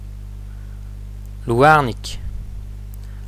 lou-ar-niK = renardeau        liste...ICI
renardeau---L.mp3